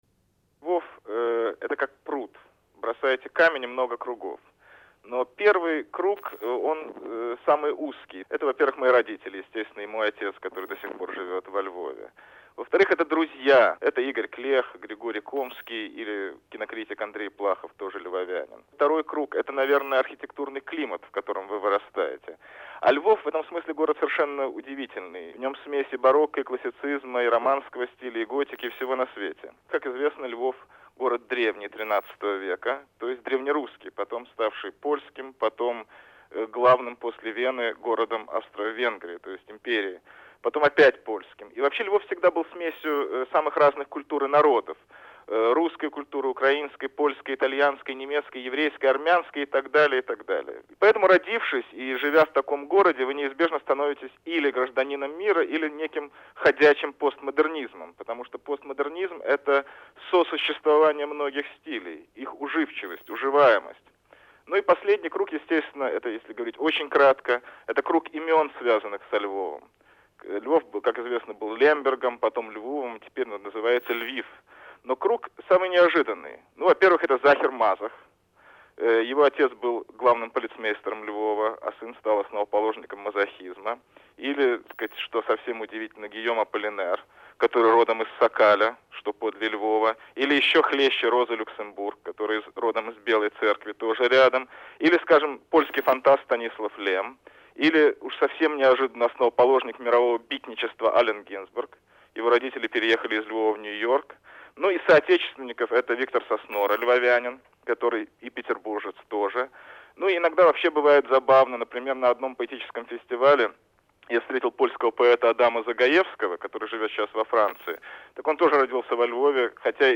Участники разговора